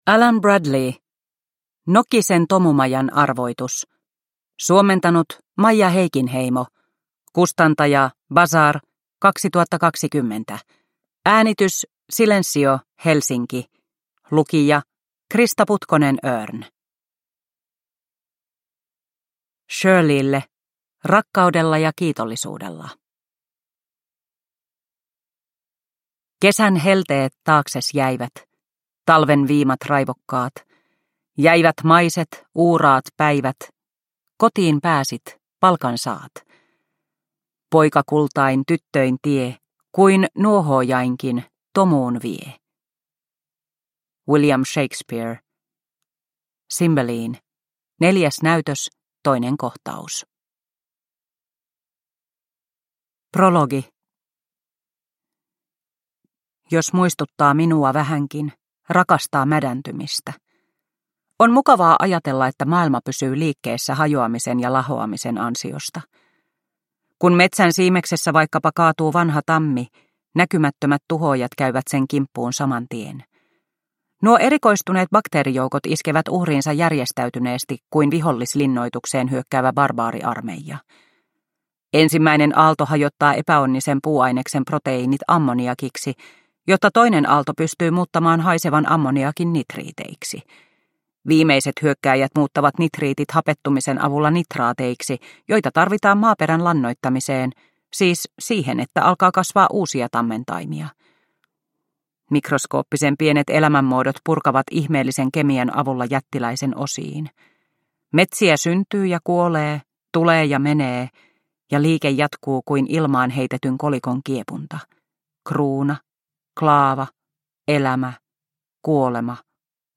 Nokisen tomumajan arvoitus – Ljudbok – Laddas ner